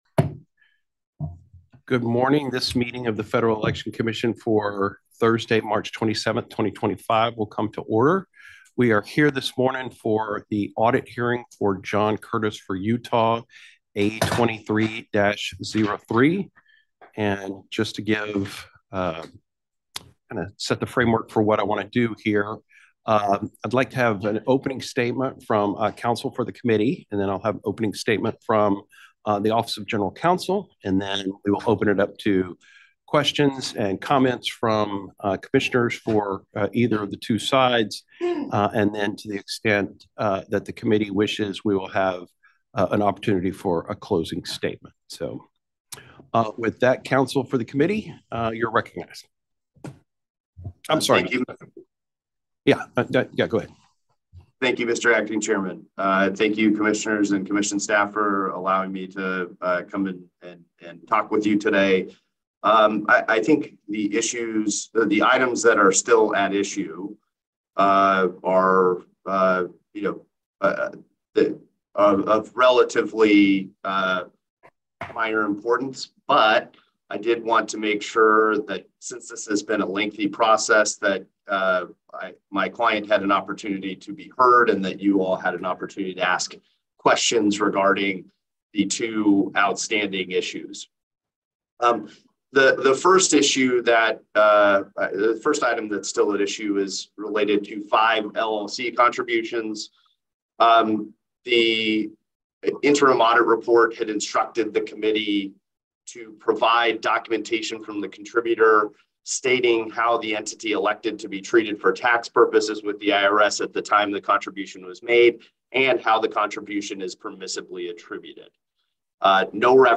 March 27, 2025 audit hearing | FEC
The Commission considers new regulations and other public matters at public hearings at FEC headquarters, 1050 First Street NE, Washington, DC.